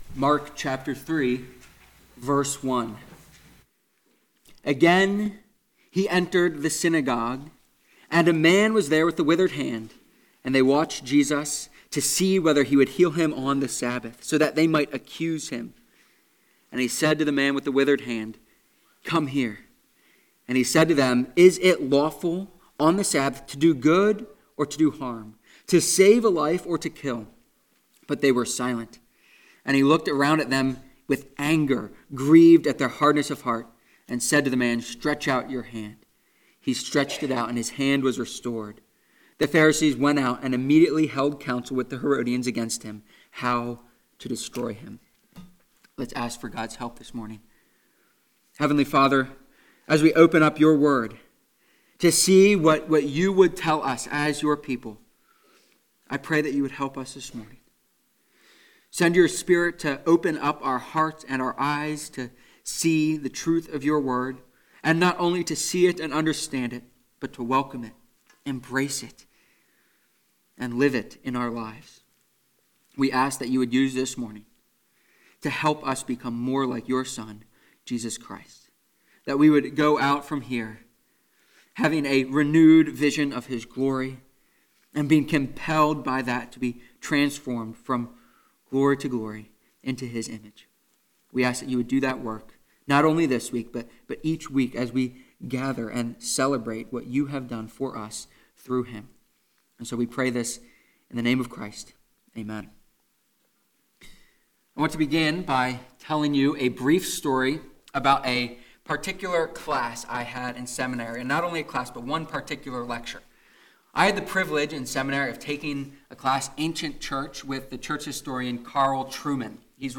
Mark-3.1-6-Sermon.mp3